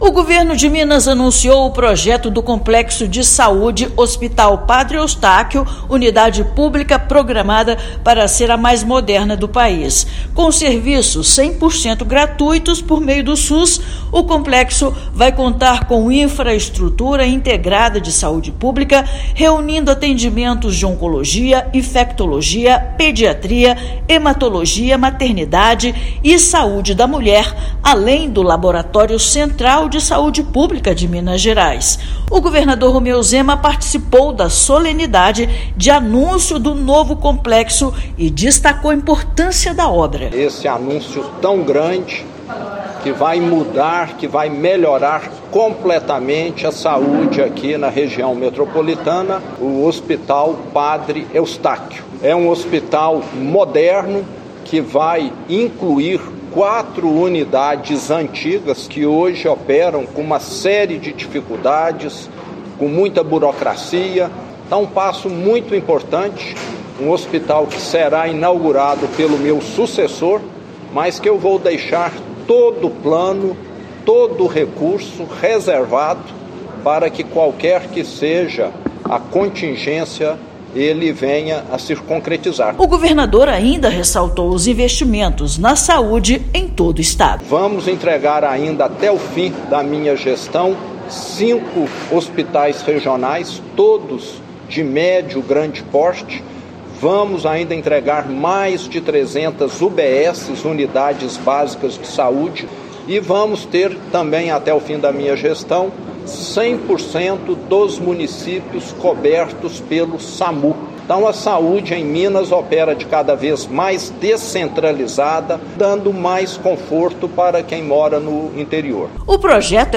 Com capacidade para mais de 500 leitos, espaço em Belo Horizonte vai oferecer serviços de cuidado hospitalar e vigilância laboratorial, garantindo assistência mais ágil à população. Ouça matéria de rádio.